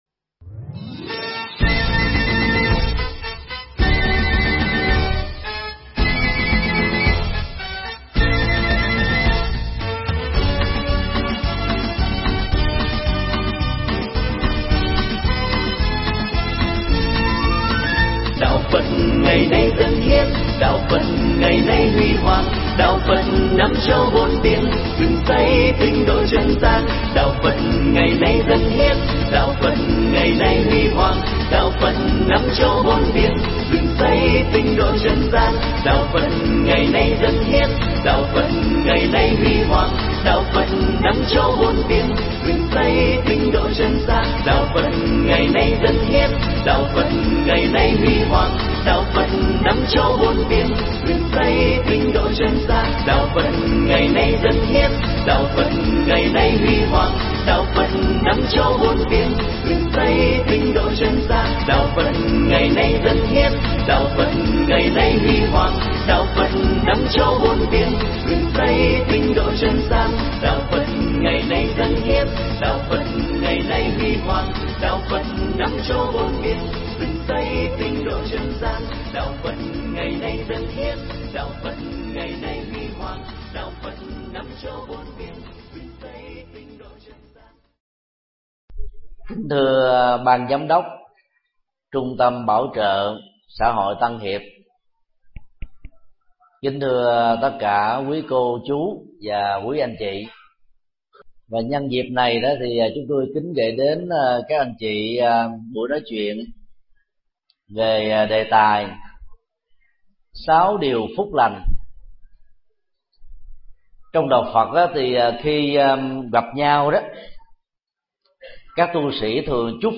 Sáu điều phúc lành – thầy Thích Nhật Từ - Mp3 thuyết pháp
Nghe mp3 pháp thoại Sáu điều phúc lành do thầy Thích Nhật Từ giảng tại Trung Tâm Bảo Trợ Xã Hội Tân Hiệp, tỉnh Bình Phước, ngày 09 tháng 01 năm 2012.